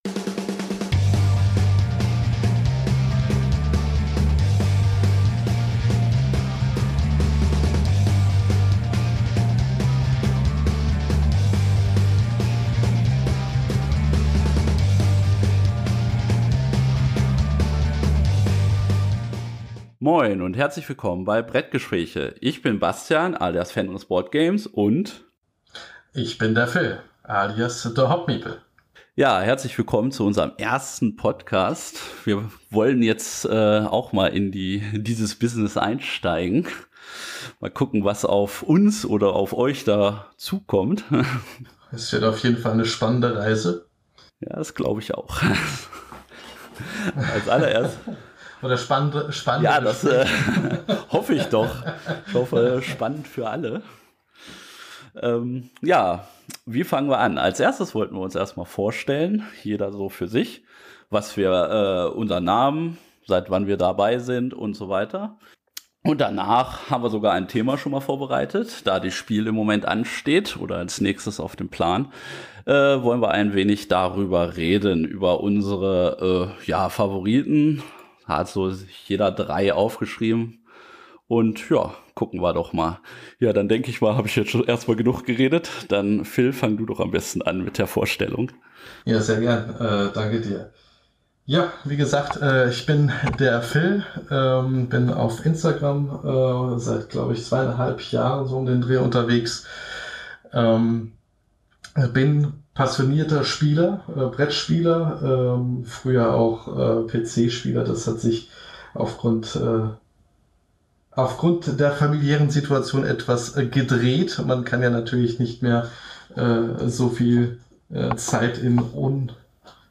Zwei begeisterte Brettspiel-Instagramer die für euch über die Bretter die die Welt bedeuten berichten.